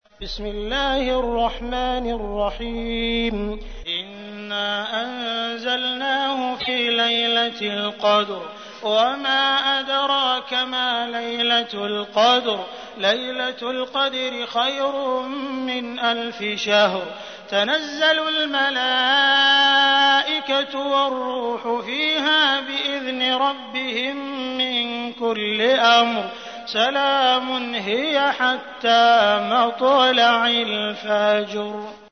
تحميل : 97. سورة القدر / القارئ عبد الرحمن السديس / القرآن الكريم / موقع يا حسين